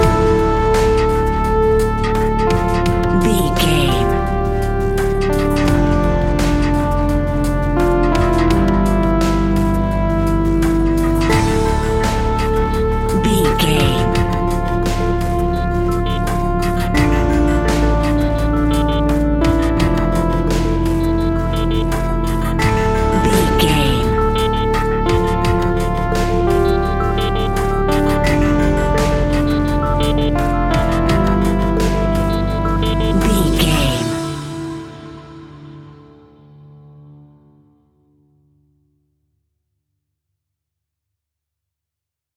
royalty free music
Aeolian/Minor
tension
ominous
dark
haunting
eerie
piano
strings
drums
percussion
synthesiser
ticking
electronic music
Horror Pads